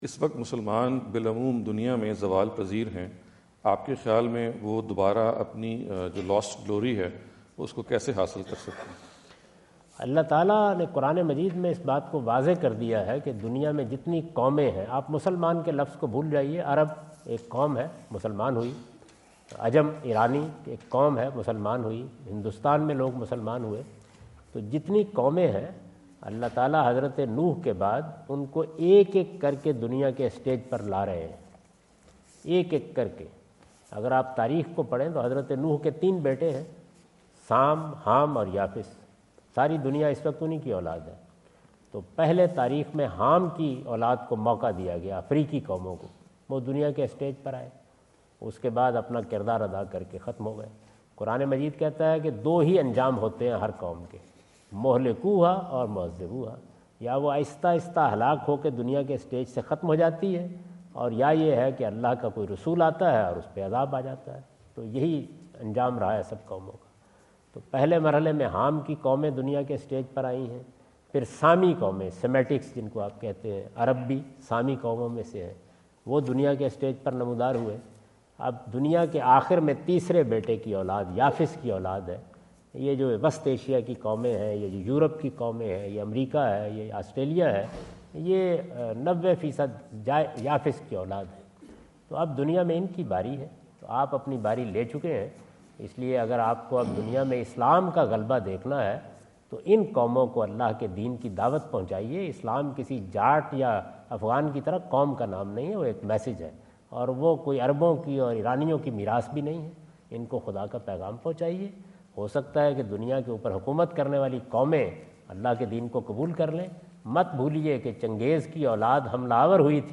In this video Javed Ahmad Ghamidi answer the question about "how can Muslims regain their glory?" asked at The University of Houston, Houston Texas on November 05,2017.